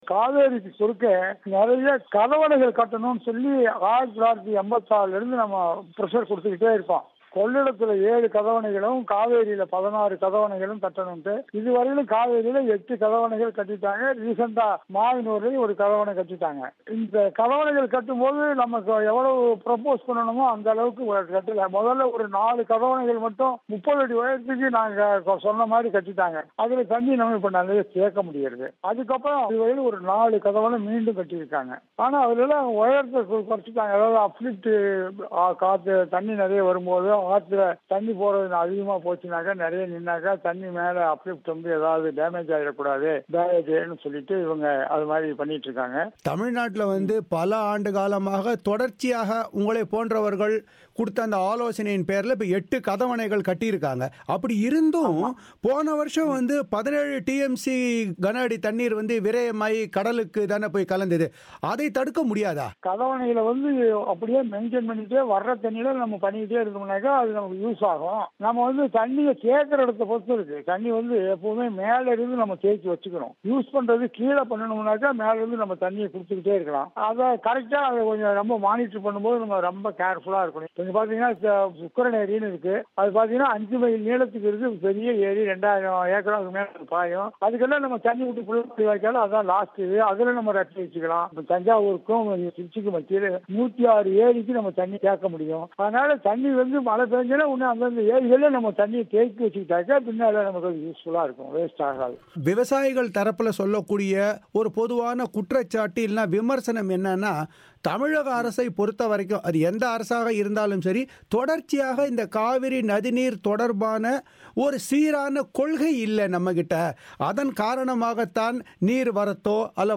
தமிழோசைக்கு வழங்கிய பேட்டி.